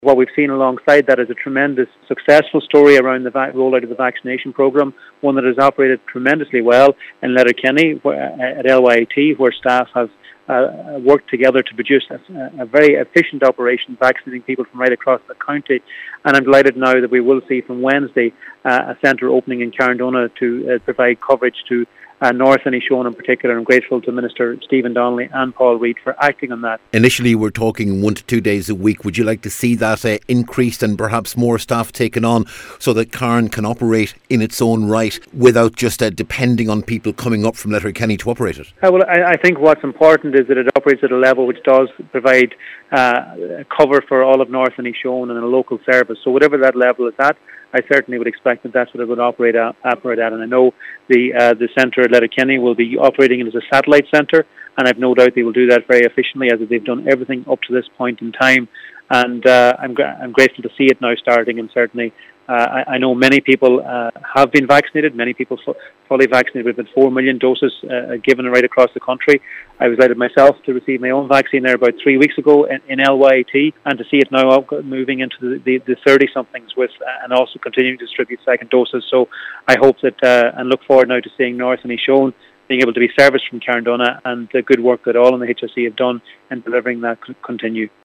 Minister McConalogue says the LYIT service in Letterkenny has operated very well since opening earlier this year, and he believes that will be continued in Carndonagh: